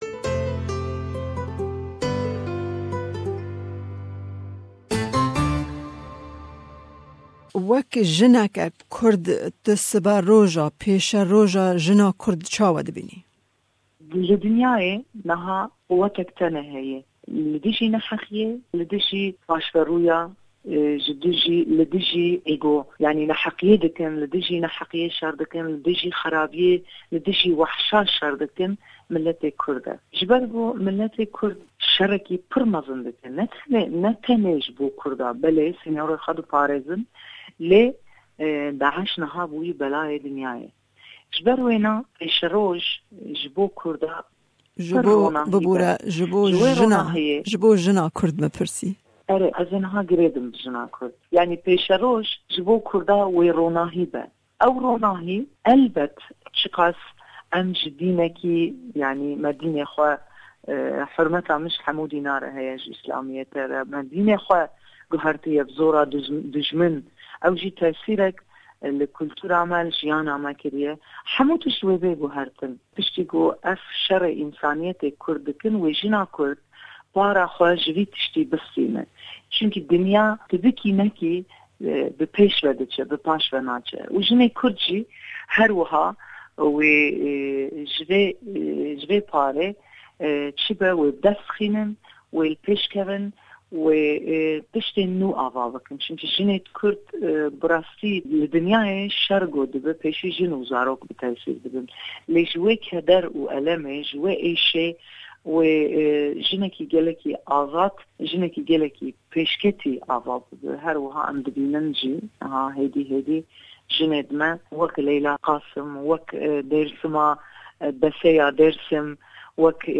Di beshê 2yem di hevpeyvîna tev Rojîn ê re behsa rol û girîngiya mafê jina kurd dike. Herweha behsa alîkariya 20 keçên kurde Êzîdî dike ku çawa xwe ji bin destê Daish rizgar kirine.